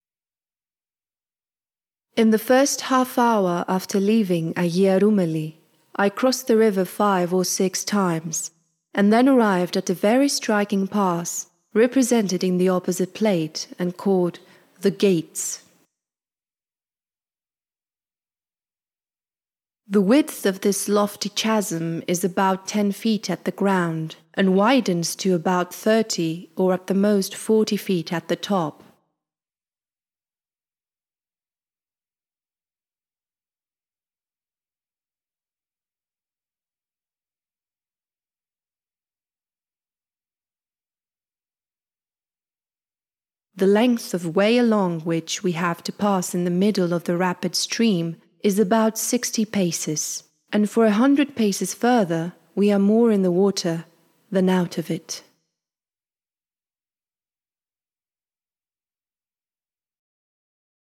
Samaria - narration